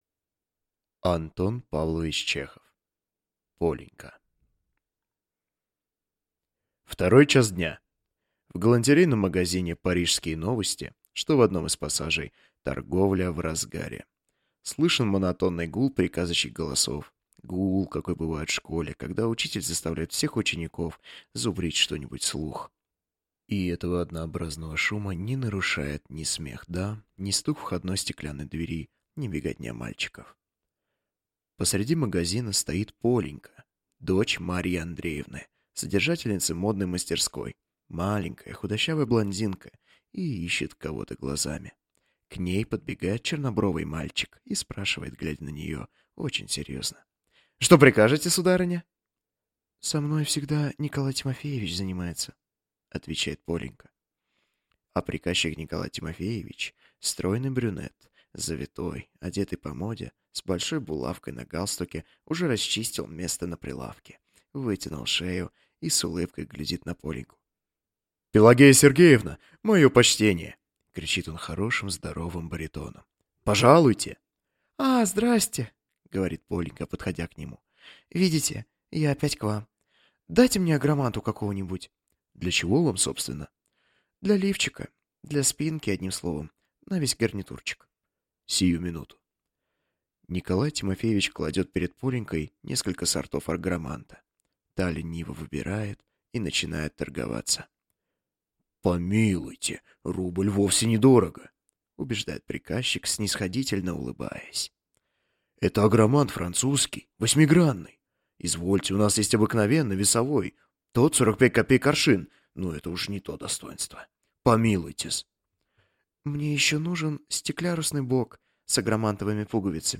Аудиокнига Полинька | Библиотека аудиокниг